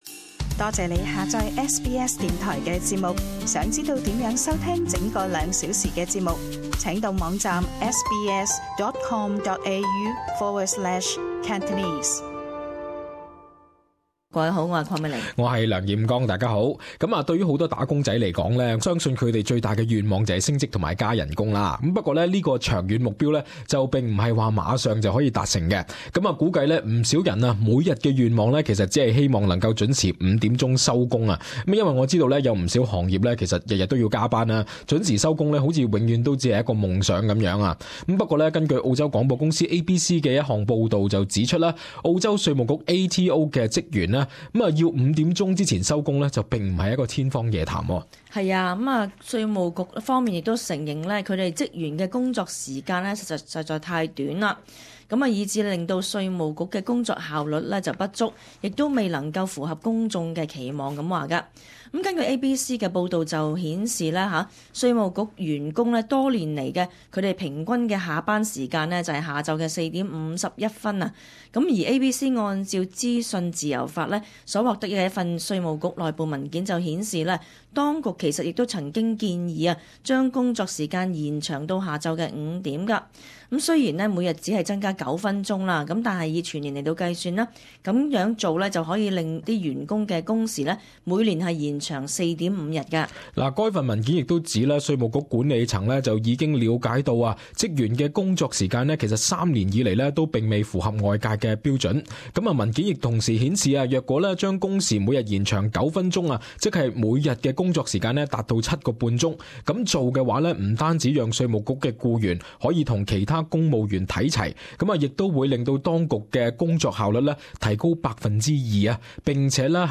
【時事報導】稅務局職員堅持每日下午4:51收工？